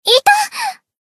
贡献 ） 分类:蔚蓝档案语音 协议:Copyright 您不可以覆盖此文件。
BA_V_Tsukuyo_Battle_Damage_3.ogg